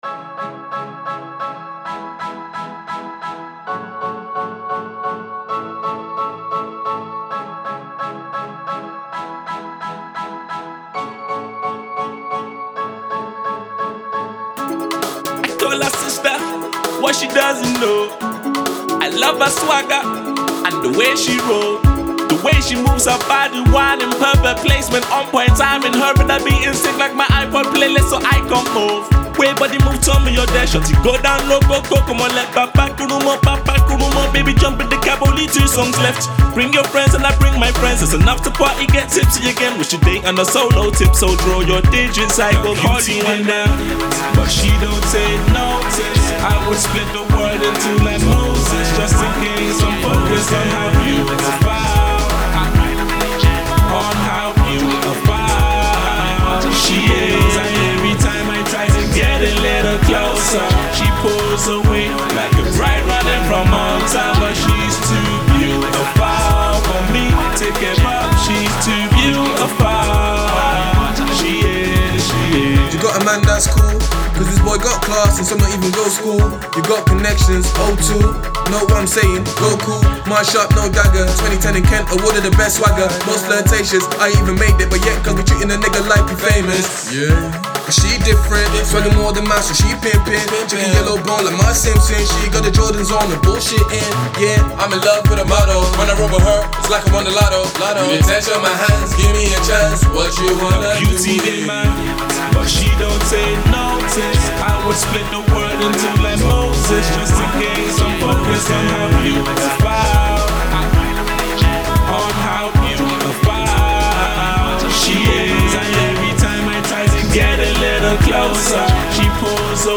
radio-friendly new school Afro-pop tune